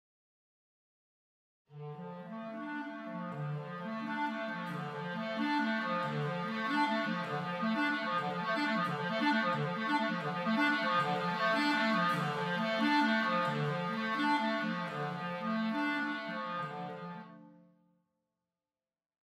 Umfangreich ist das Angebot an Arpeggien:
Diese können zum Tempo synchron oder auch mit variabler Geschwindigkeit gespielt werden.